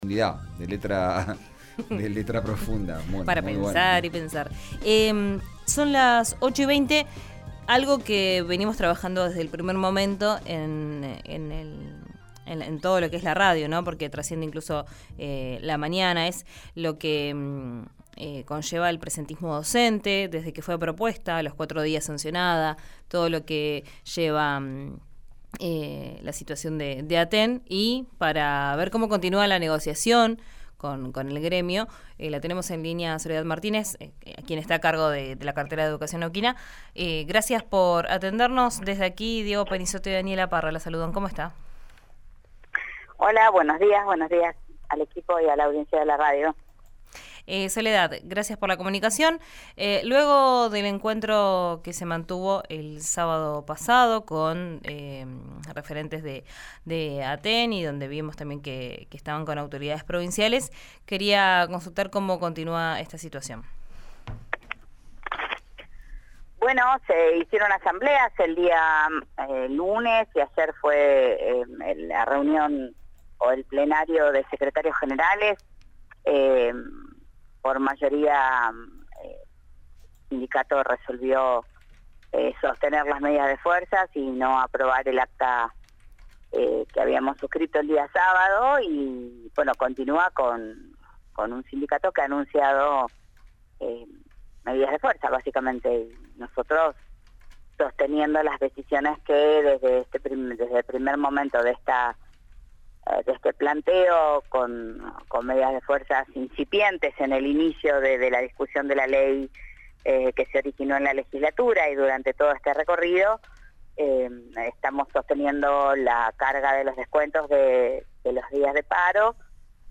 Escuchá a Soledad Martínez, ministra de Educación de Neuquén, por RÍO NEGRO RADIO:
La funcionaria, en diálogo con RÍO NEGRO RADIO, explicó que desde el gobierno se sostienen las decisiones que, desde el primer momento se adoptaron cuando el sindicato docente neuquino lanzó el plan de lucha.